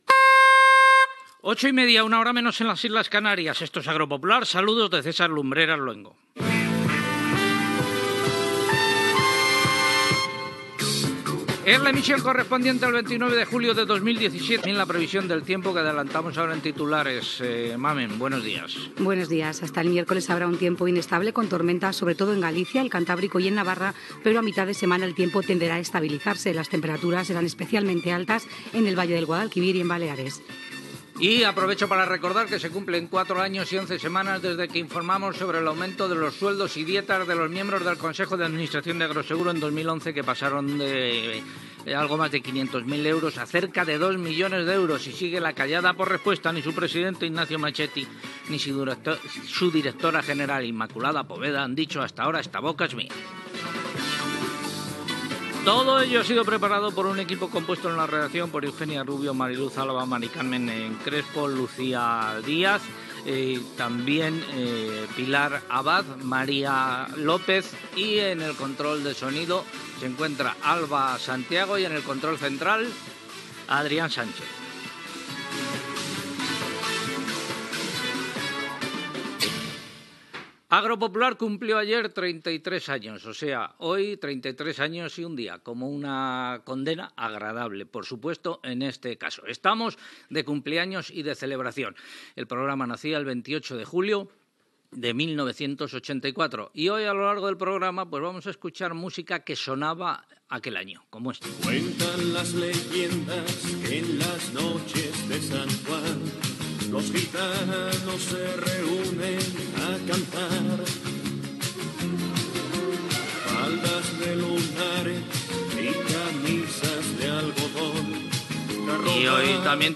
Hora, identificació del programa, data, estat del temps, equip, el programa compleix 33 anys, tema musical, pregunta de concurs, formes de participar i regal, opinions de l'audiència publicades a Twitter i Facebook, tema musical
Informatiu
FM